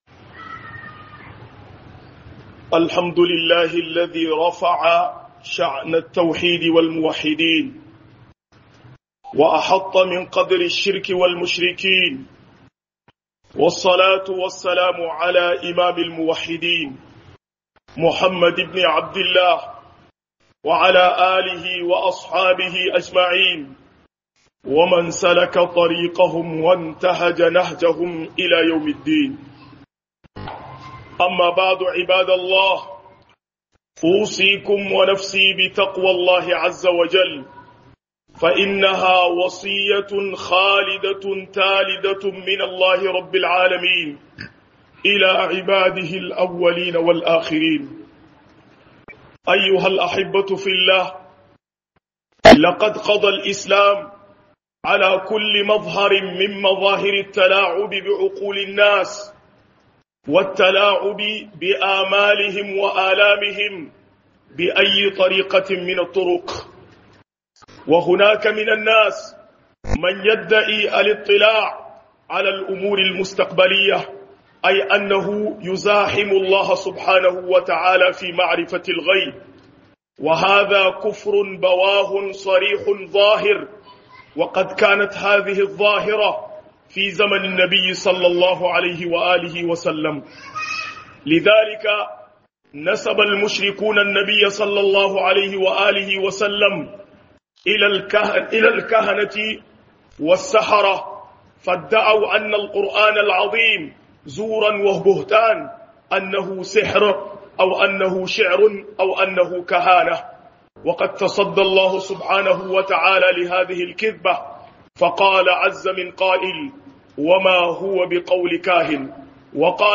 001-إدِّعاء معرفة الغيب بطرق غير مشروعة كفر ضواح - خطبة الجمعة